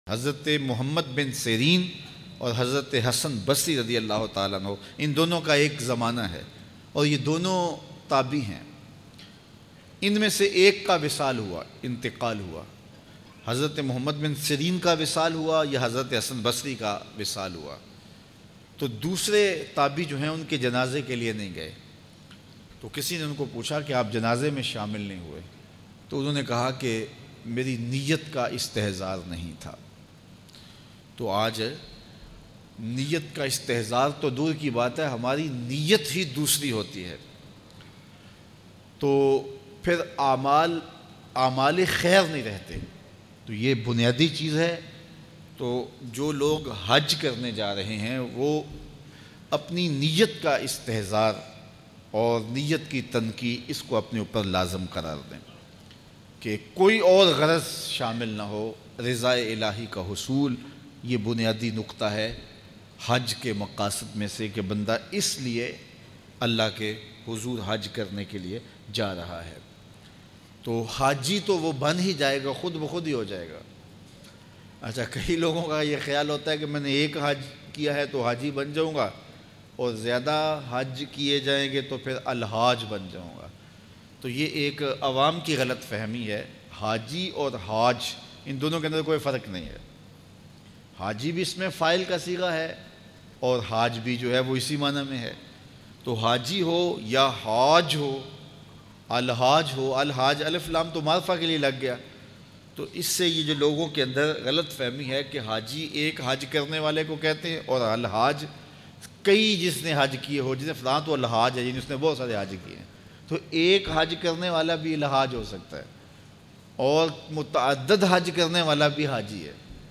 1Tabai ne apne sathi ka jnaza kio na parha Bayan